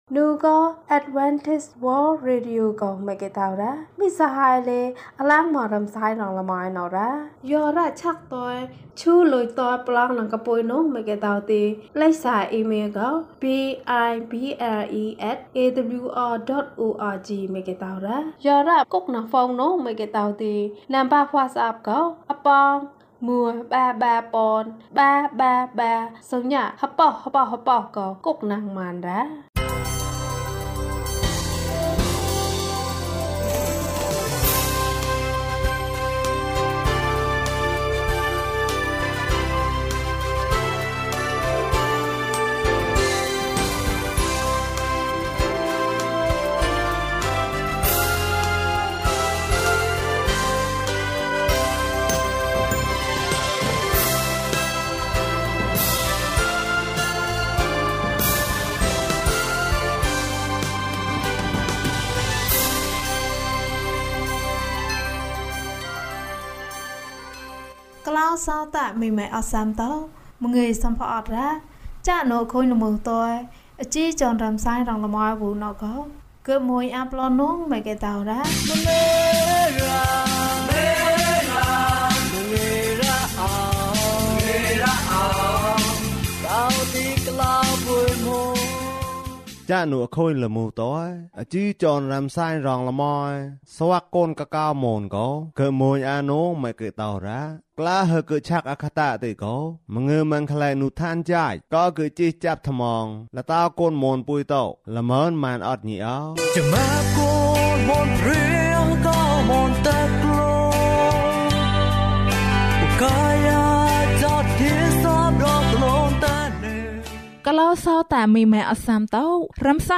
သမ္မာကျမ်းစာဇာတ်လမ်း။၀၁။ ကျန်းမာခြင်းအကြောင်းအရာ။ ဓမ္မသီချင်း။ တရားဒေသနာ။